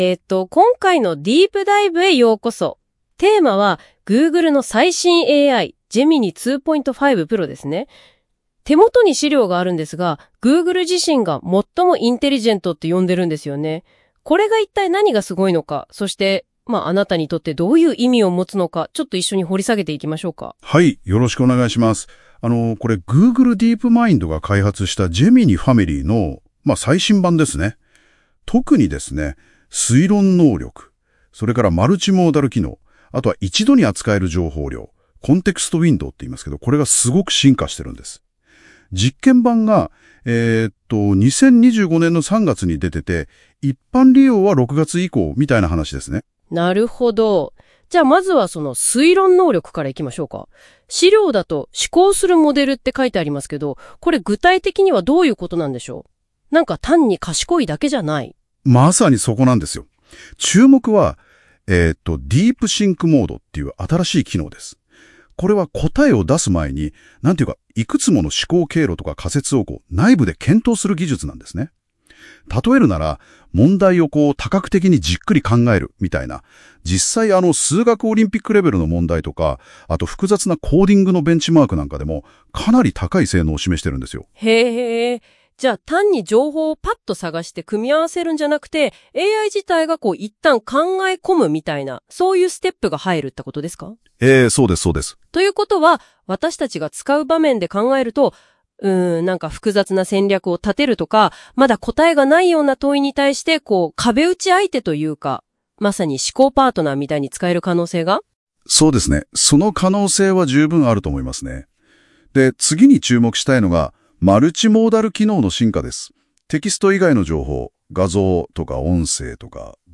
また、下記の音声ファイルは数日前に実装されたDeep Researchのポッドキャスト機能を使って生成した音声となっている。